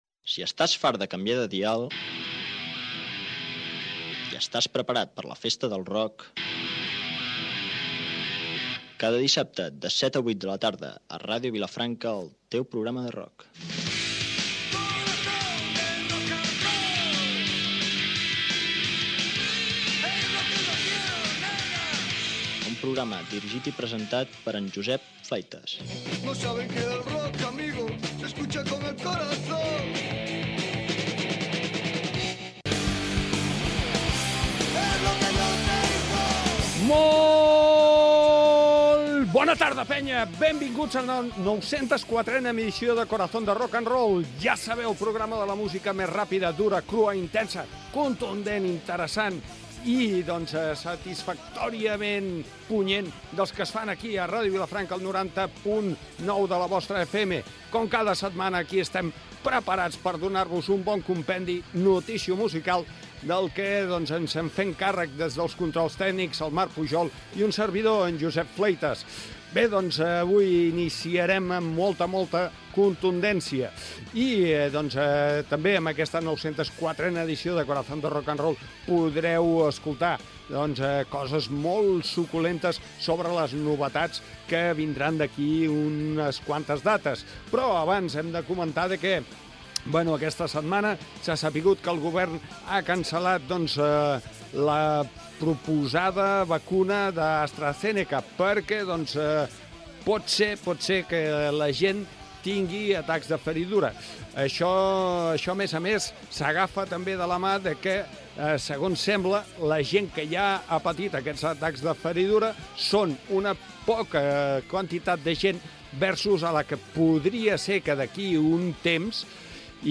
Careta del programa, presentació de l'edició 904, equip, comentari de la vacuna contra la Covid-19 dAstra Zeneca i els seus efectes secundaris
Musical